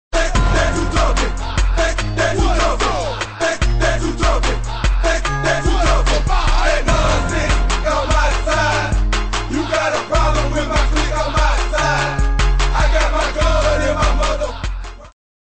Rap & Hip Hop
Southern hip hop, Crunk, Rap